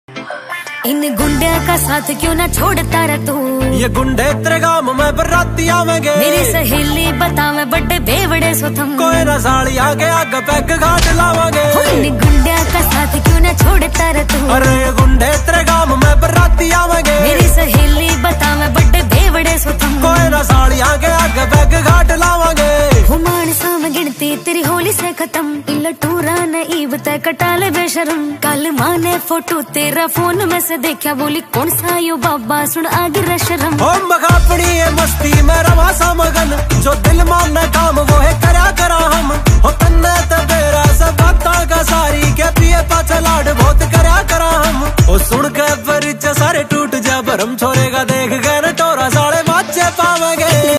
New Haryanvi ringtone || Haryanvi Song